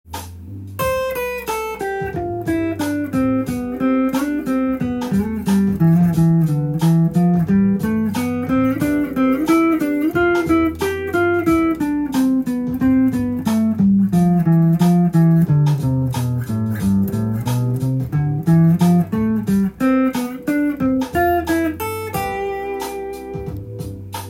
８分音符でもドレミを適当に弾いてみます。
ひたすら同じ音符を並べます。